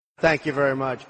Download Trump Thank You Very Much sound effect for free.